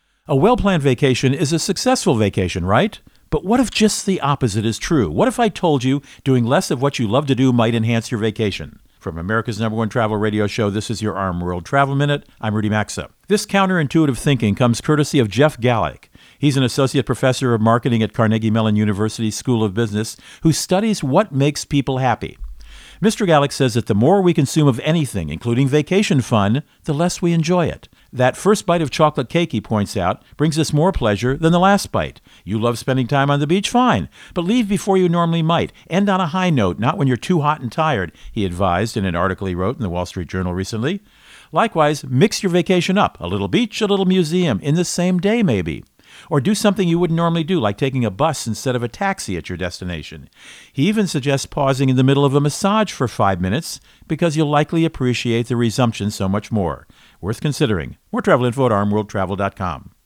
Co-Host Rudy Maxa | Enjoying a Vacation is an Art